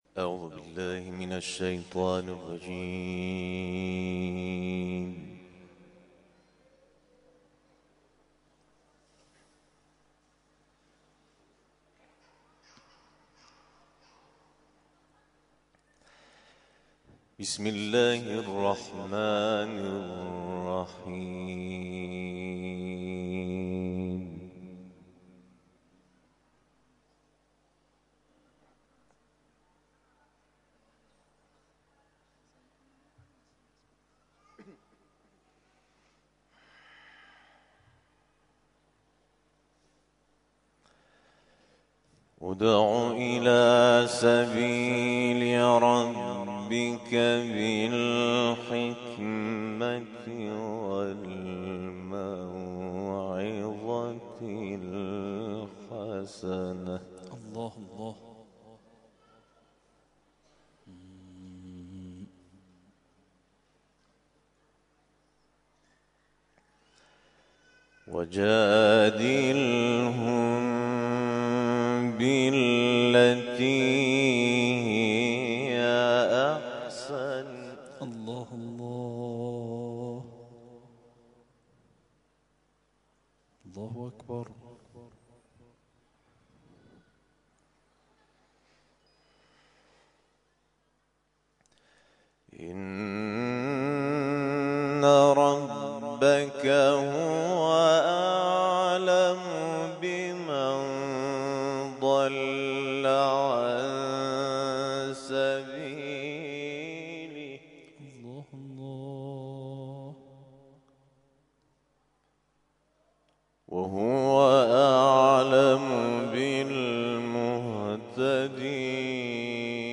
جدیدترین تلاوت
محفل انس با قرآن کریم در آستان عبدالعظیم الحسنی(ع)